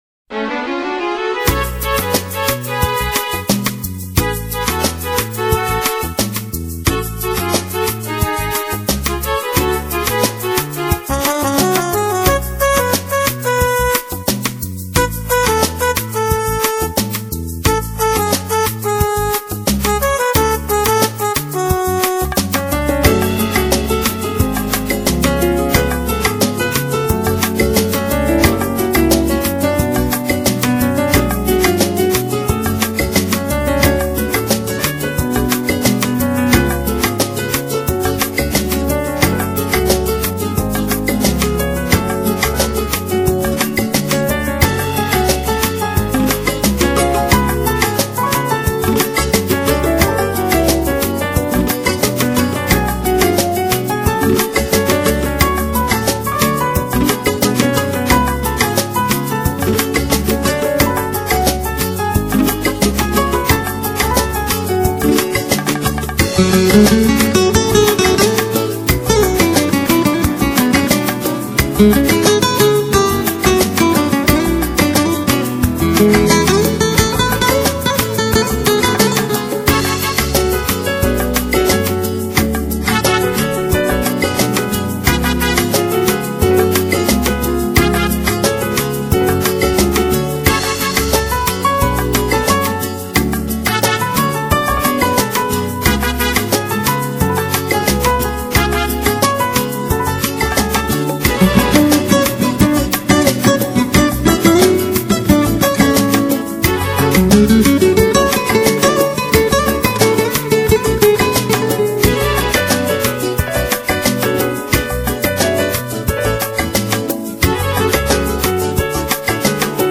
流　　派： New Age